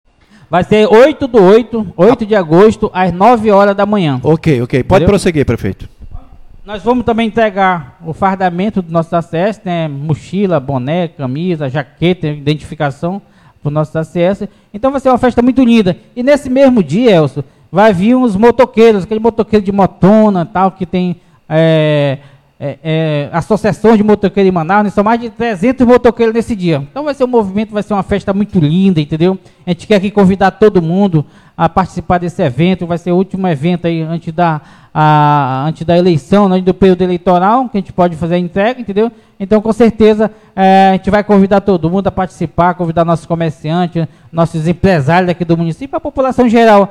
Ao participar de uma Live o Prefeito do Município fez o convite para a população participar da festa, que segundo ele contará ainda com a presença de mais de 300 motoqueiros de Manaus, ouça o áudio do prefeito do Careiro convidando a população: